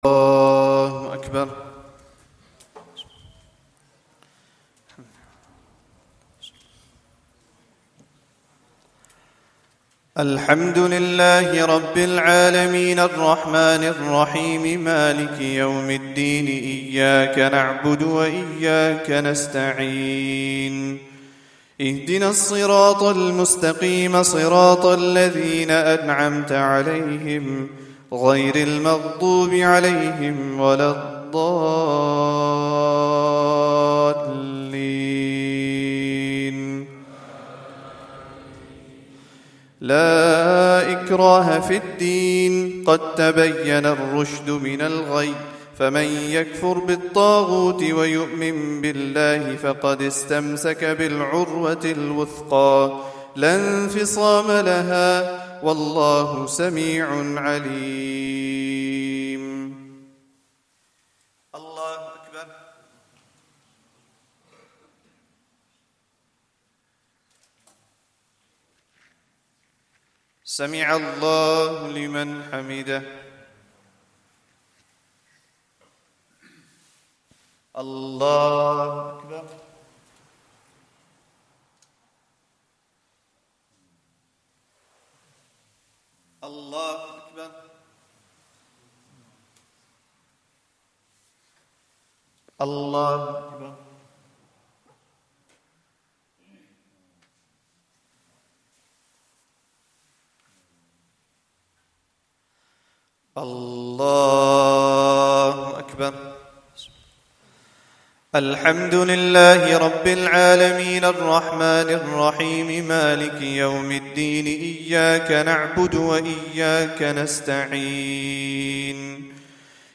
Day 29 - Taraweeh 1444 - Recital